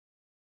silence.wav